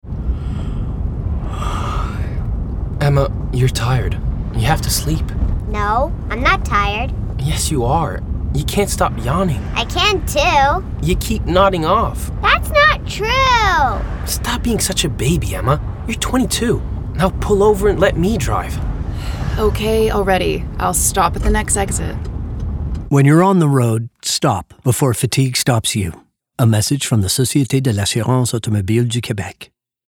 Publicité (SAAQ) - ANG